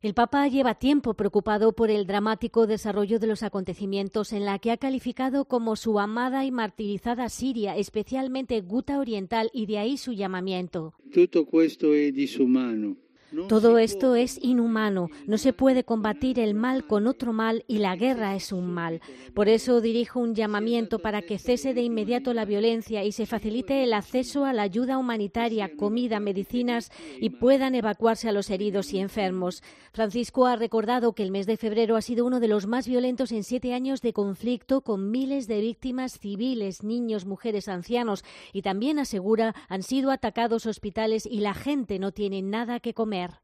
AUDIO: Farncisco clama por la paz en Siria. Escucha la crónica